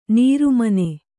♪ nīru mane